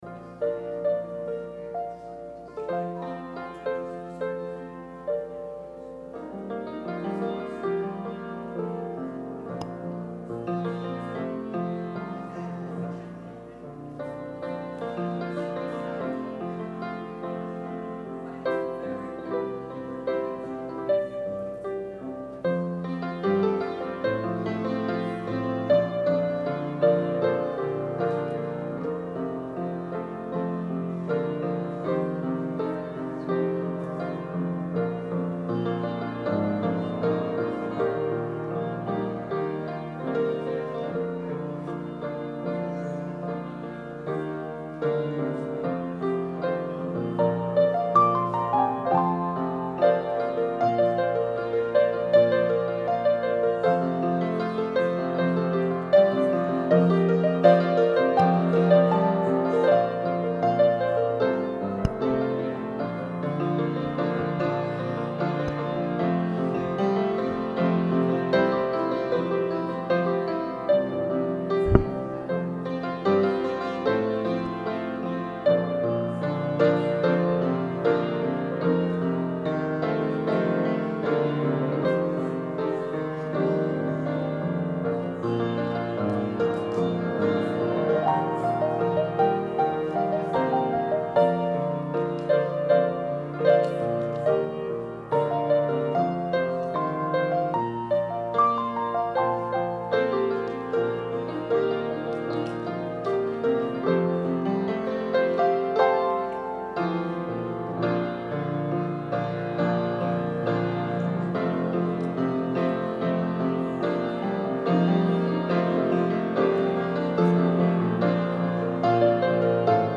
Sermon – May 26, 2019